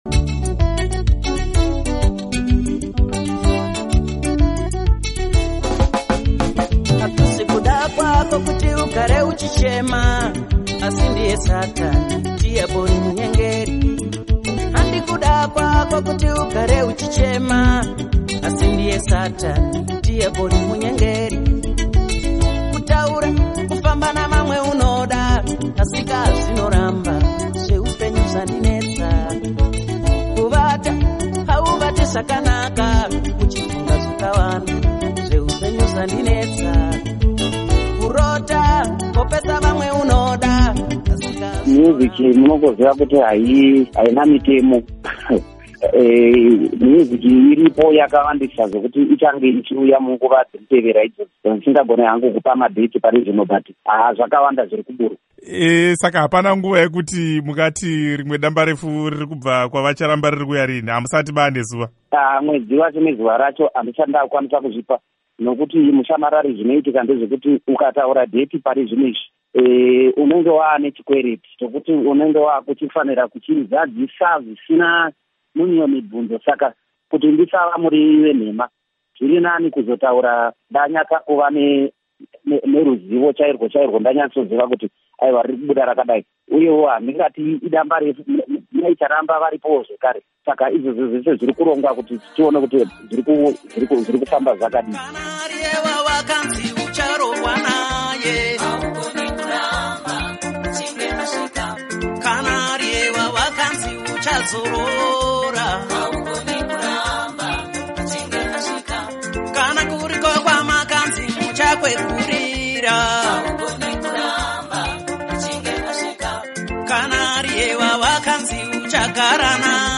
Hurukuro naBaba Charles Charamba